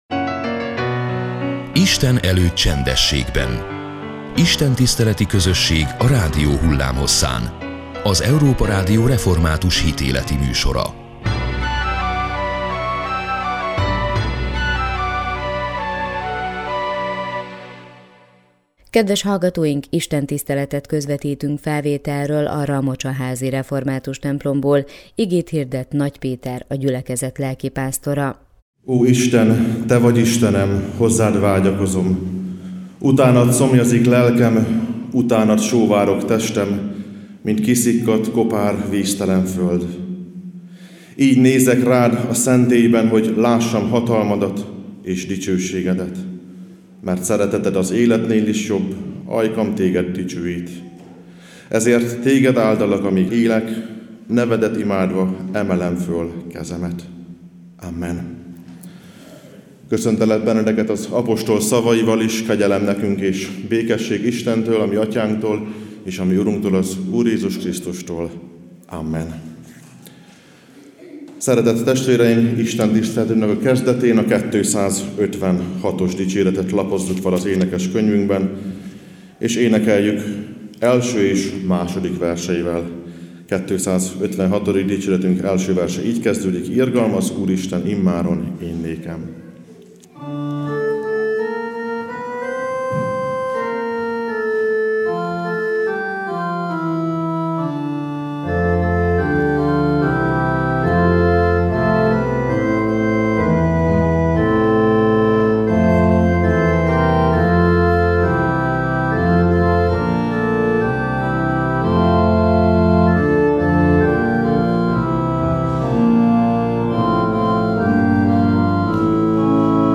Istentiszteleti közösség a rádió hullámhosszán. Az Európa Rádió hitéleti műsora minden vasárnap és a református egyház ünnepnapjain.